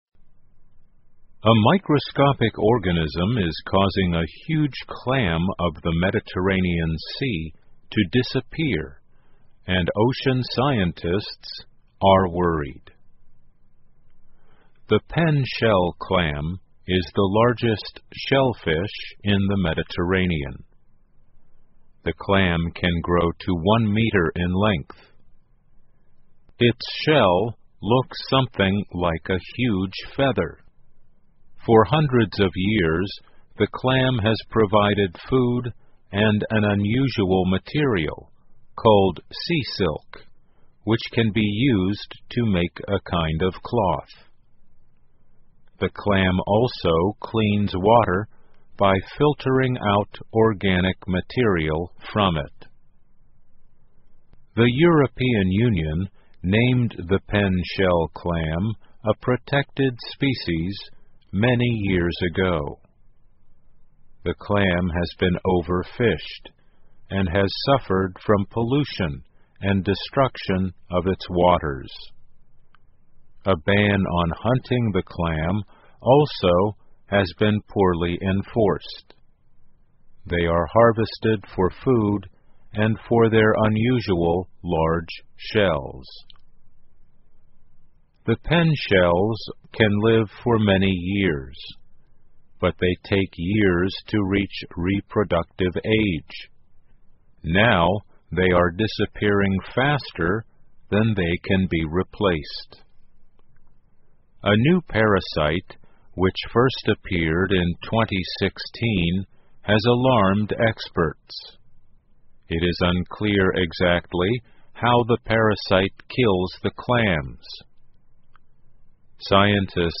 VOA慢速英语2018 神秘生物威胁巨型地中海蛤蜊 听力文件下载—在线英语听力室